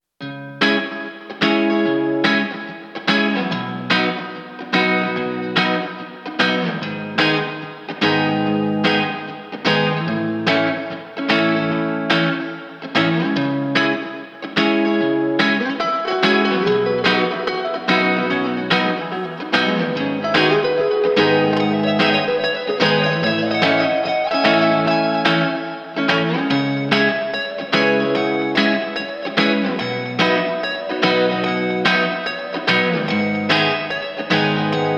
2016-09-23 Жанр: Альтернатива Длительность